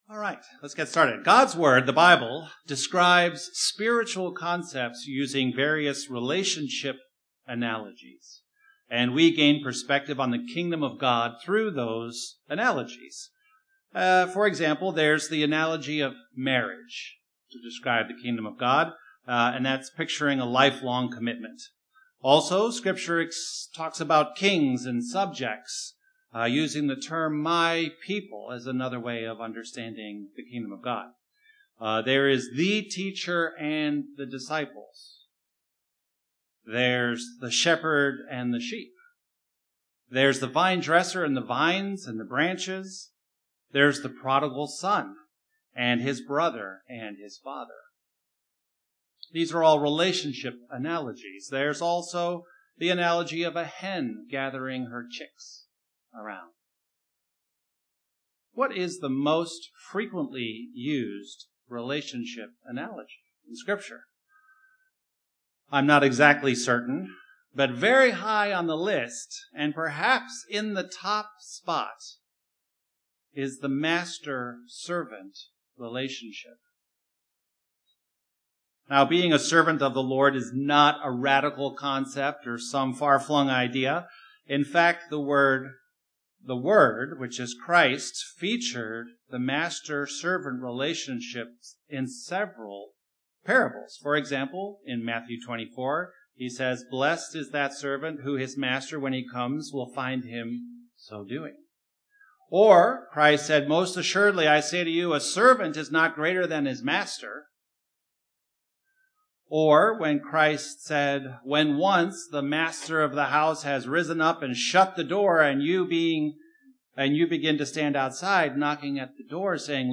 Given in Northwest Indiana